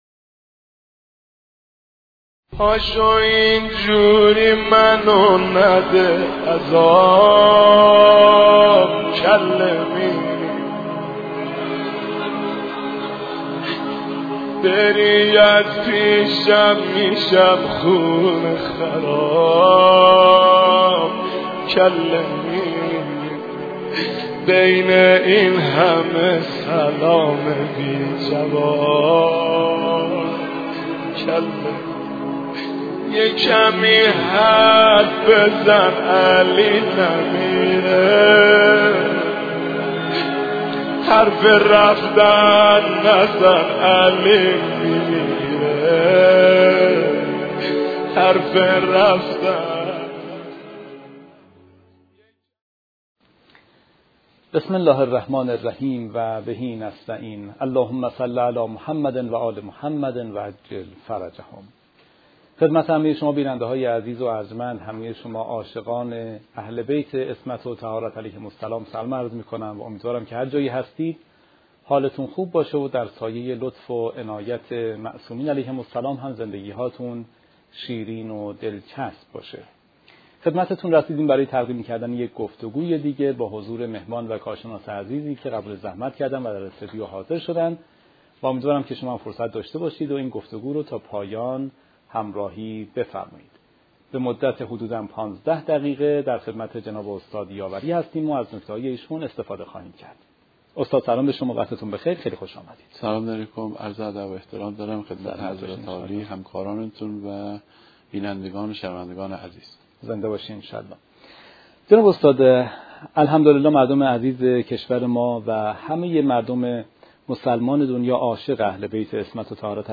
این گفتگو به بررسی سبک زندگی و سیره اجتماعی حضرت فاطمه زهرا(سلام الله علیها) به عنوان یک الگوی کامل و کاربردی برای جامعه امروز میپردازد.